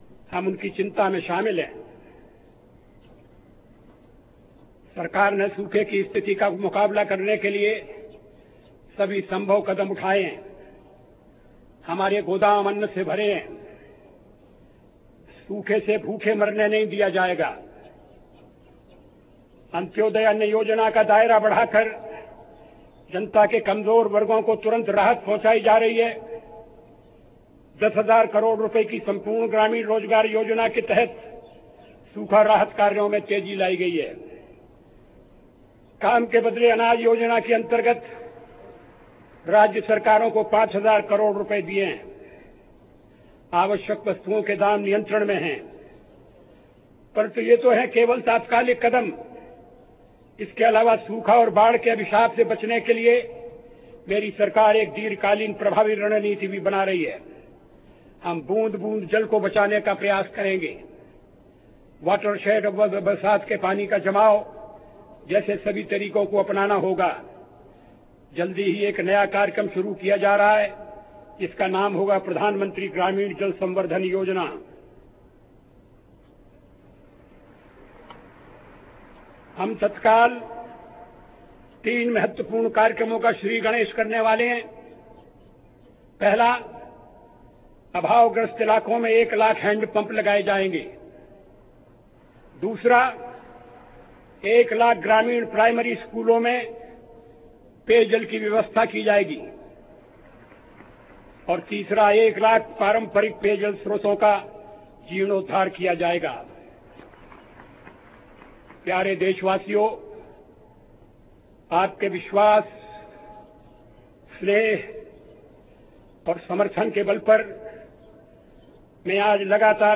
Prime Minister Atal Behari Vajpayee today made a diplomatic move by not spewing venom against Pakistan in his Independence Day speech from the ramparts of Red Fort, though he minced no words in criticising Islamabad’s double-standards of terrorism and declaring that "we will defeat the forces of terrorism".